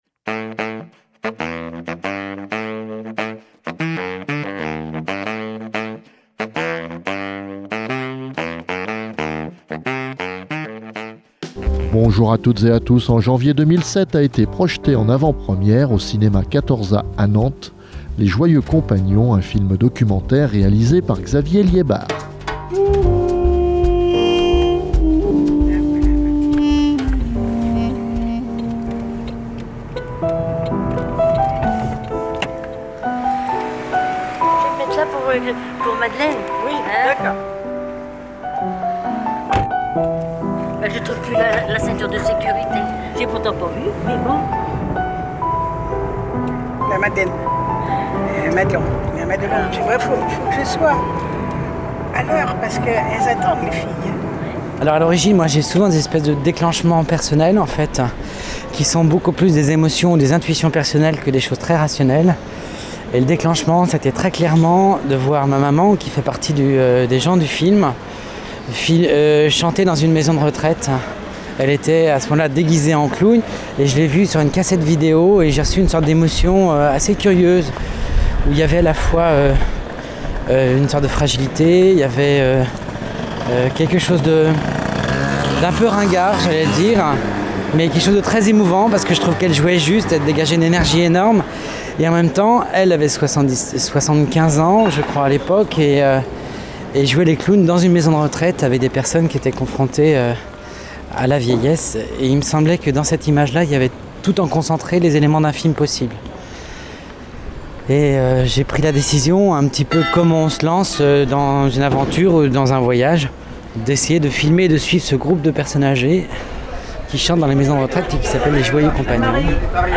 Et pour ce numéro de de Rien à voir, magazine de la rédaction d'Alternantes fm, nous vous proposons en nouvelle attention de diffuser une interview enregistrée en 2017 lors de la présentation à l 'espace cosmopolis de ; Saraba, un webdocumentaire :